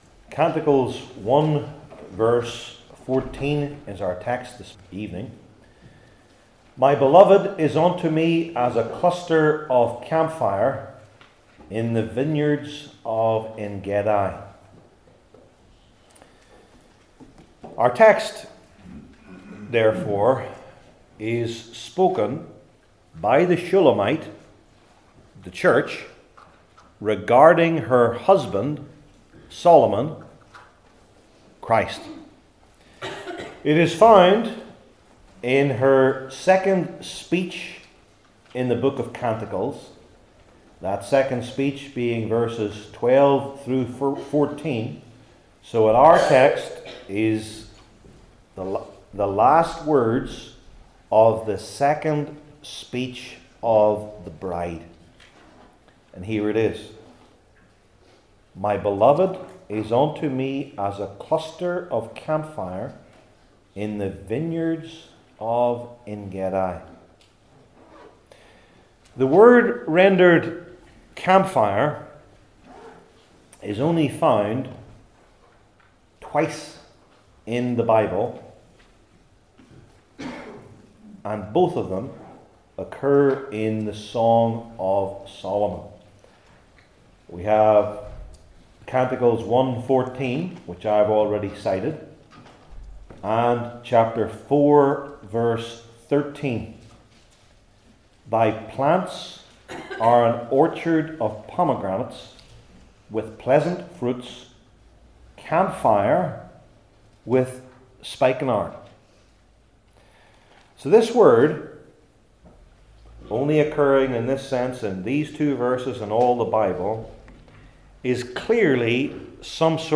Song of Solomon 1:14 Service Type: Old Testament Sermon Series I. Richly Attractive II.